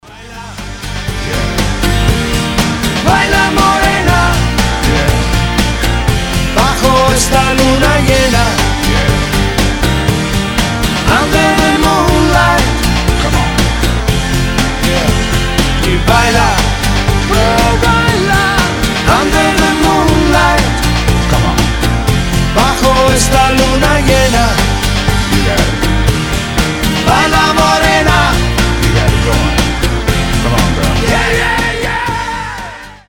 • Качество: 320, Stereo
испанские
блюз